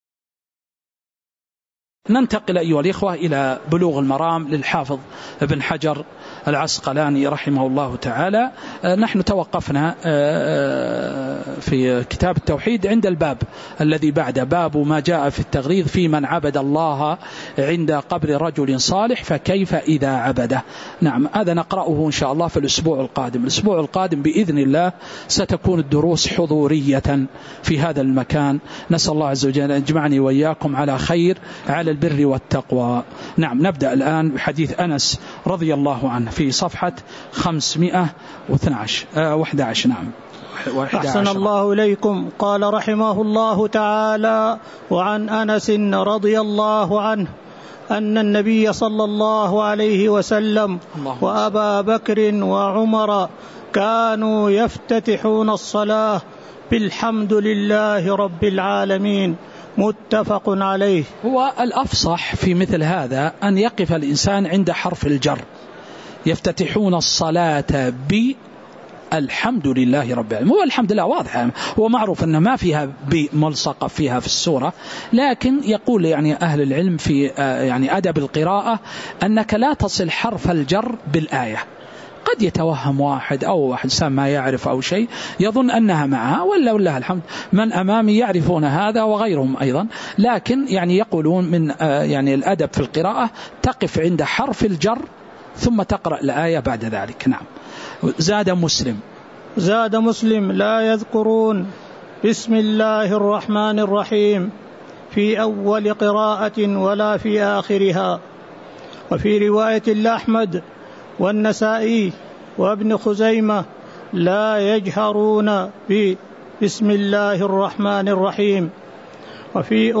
تاريخ النشر ٢٤ ربيع الأول ١٤٤٥ هـ المكان: المسجد النبوي الشيخ